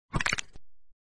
Bone Crack - Free Sound Effect - MemeHub
Bone Crack